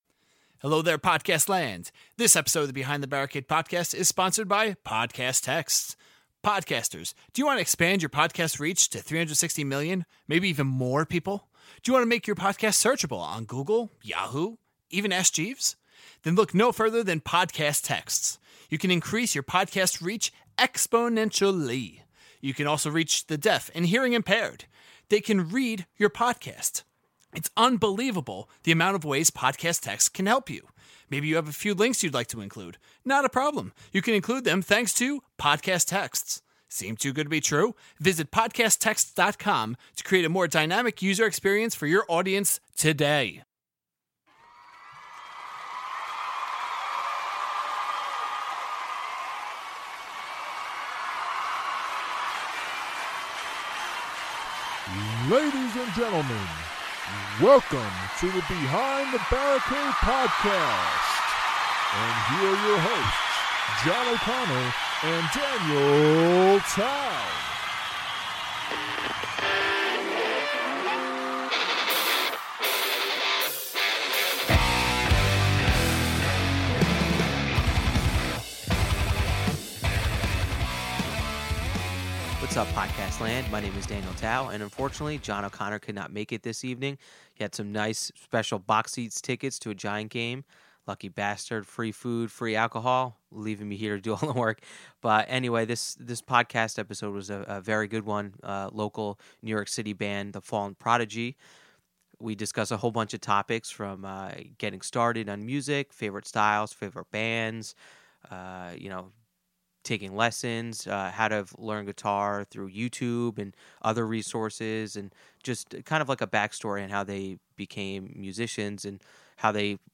Episode 81 of the podcast features New York City metal band The Fallen Prodigy! We talk to them about getting started on music, taking lessons, learning guitar on YouTube, opening for a now national touring act and more!